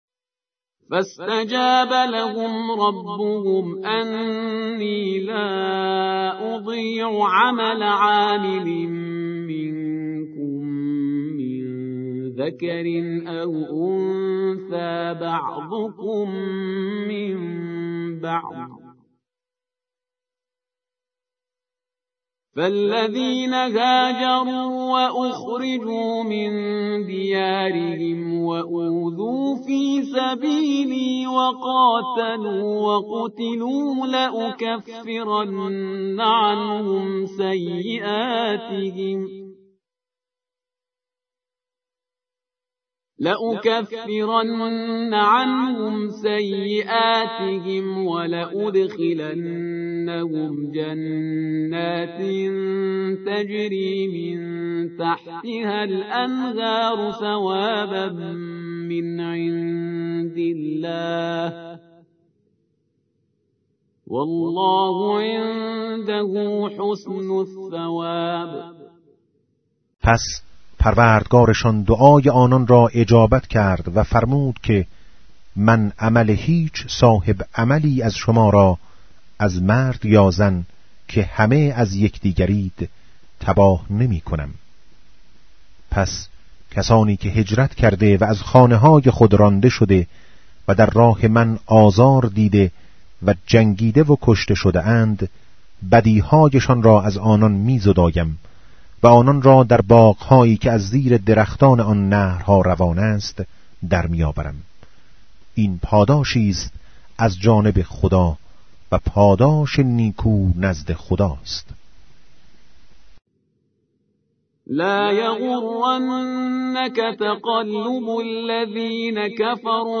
به گزارش آوای سیدجمال، از آنجا که پیامبر اکرم(ص)، در آخرین وصیت خود، از قرآن به‌عنوان ثقل اکبر یاد کرده و تأکید بر توجه به این سعادت بشری داشت،  بر آن شدیم در بخشی با عنوان «کلام نور» تلاوتی از چراغ پرفروغ قرآن كه تلألو آن دل‌های زنگار گرفته و غفلت زده را طراوتی دوباره می‌بخشد به صورت روزانه تقدیم مخاطبان خوب و همیشه همراه آوای سیدجمال کنیم.